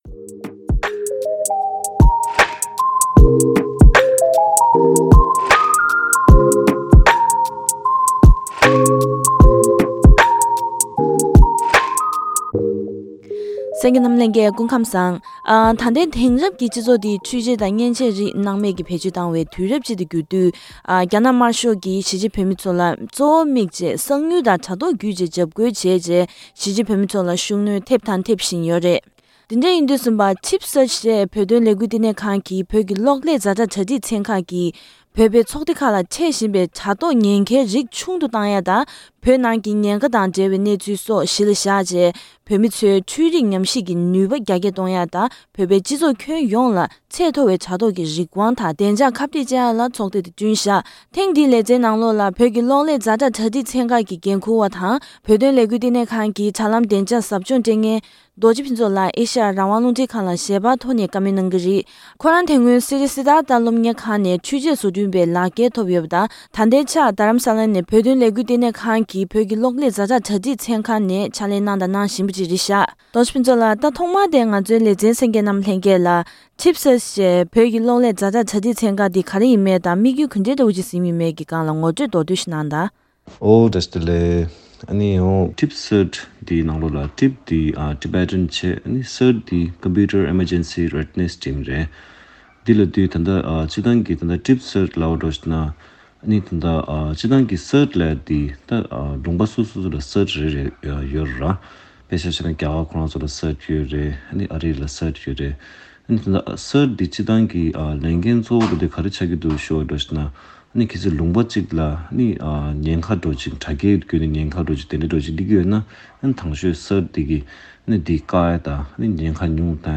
བཀའ་དྲི་ཞུས་པ་ཞིག་གསན་རོགས་གནང་།